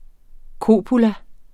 kopula substantiv, intetkøn Bøjning -et, -er, -erne Udtale [ ˈkoˀpula ] Oprindelse af latin copula 'bånd' Betydninger 1.